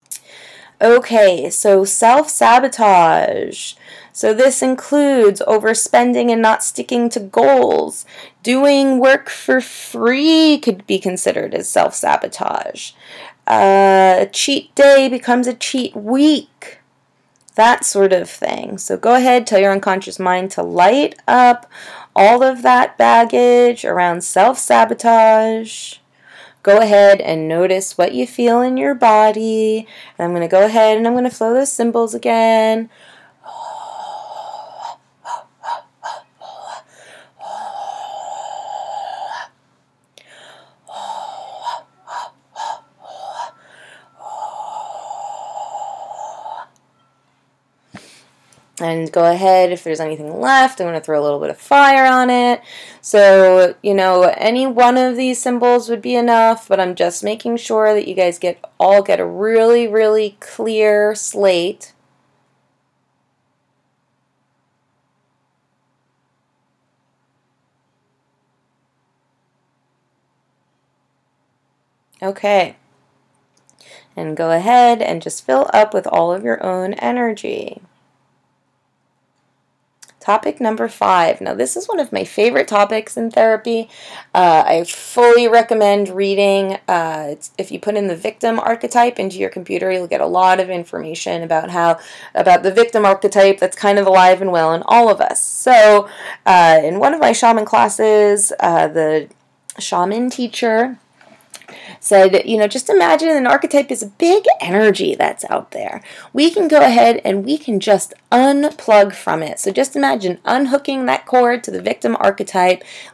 Self help audio books by Ascension Psychology